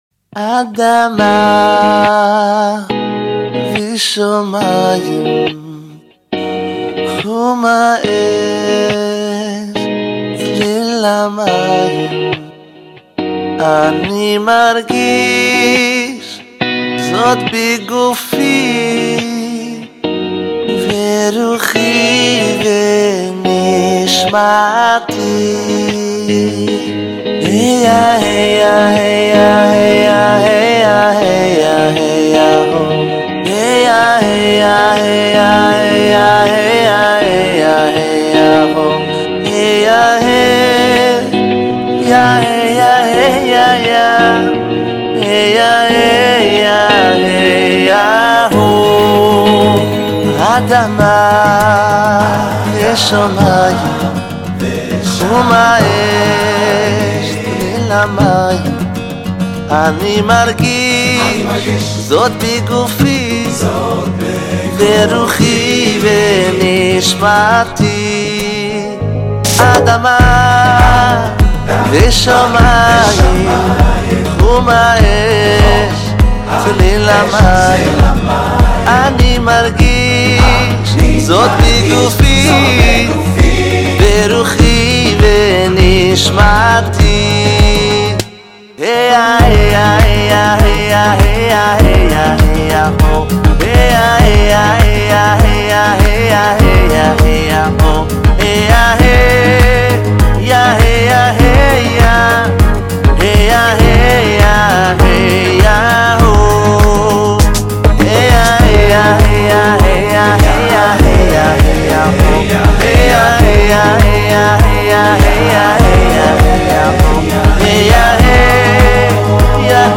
בשישה סגנונות שונים